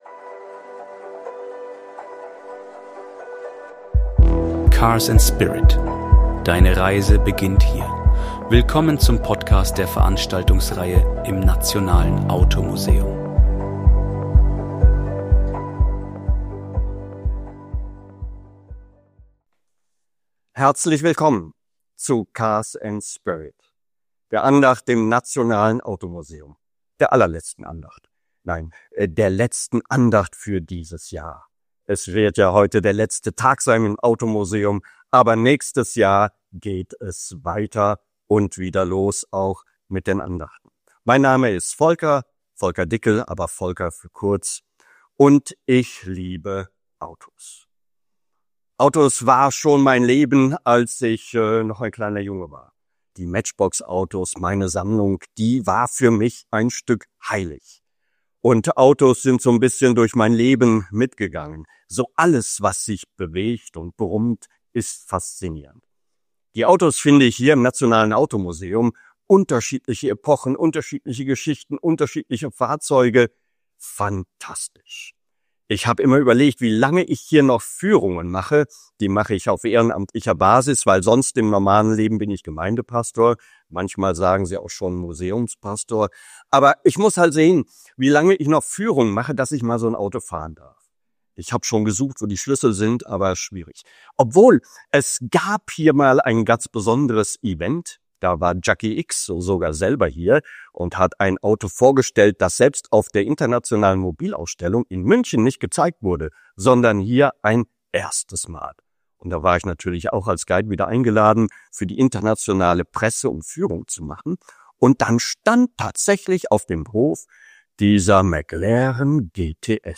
In dieser letzten Cars and Spirit-Andacht des Jahres im Nationalen Automuseum erzählt er die unglaubliche Geschichte von Giovanni Bracco, der 1952 mit einem Ferrari 250 S die Mille Miglia gewann – trotz falscher Reifen, Regen und... Grappa!